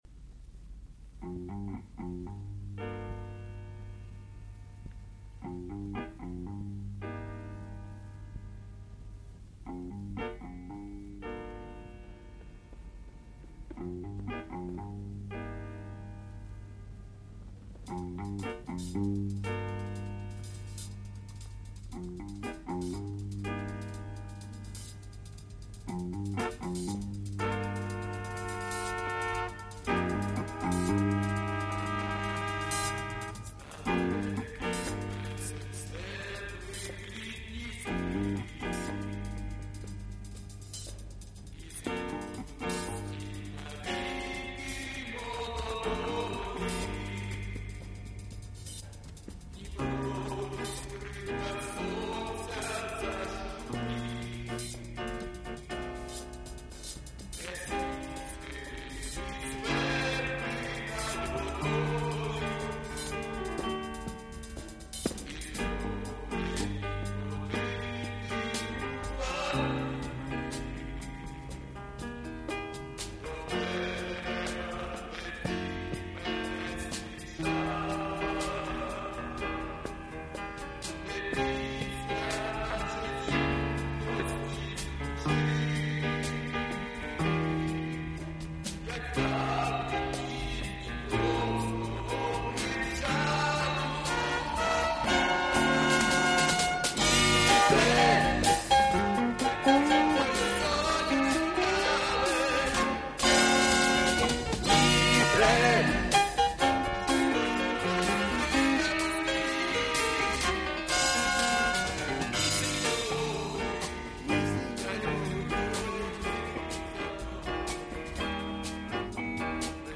Запис 1976 р.